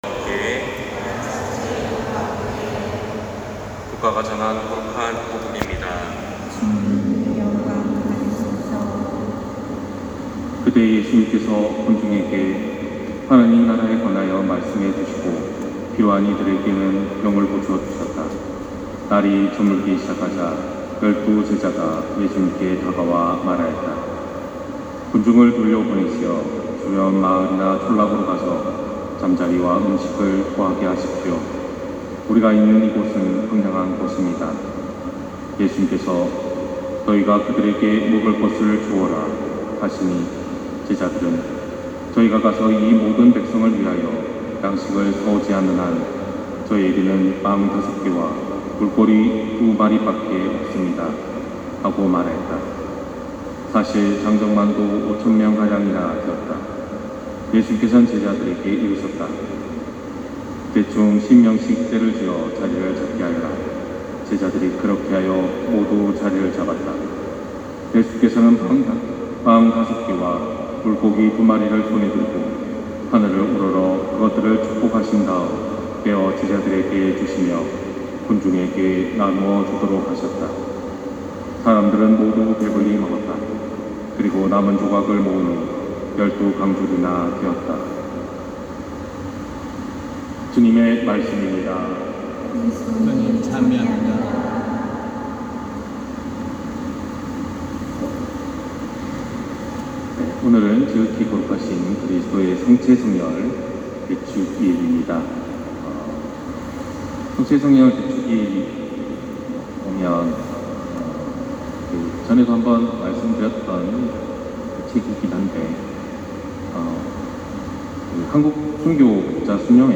250621 신부님 강론말씀